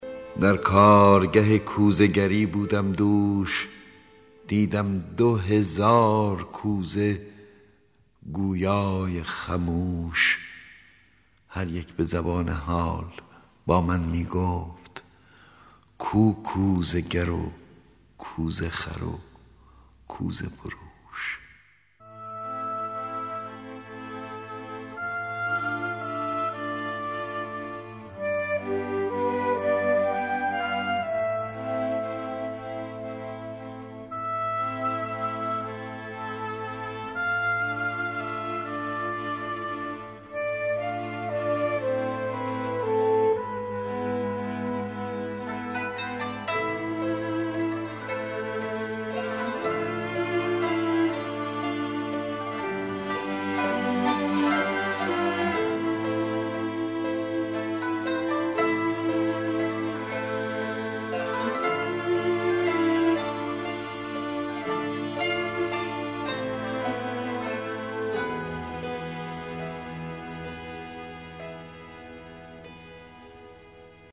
دانلود دکلمه در کارگه کوزه گری با صدای احمد شاملو
گوینده :   [احمد شاملو]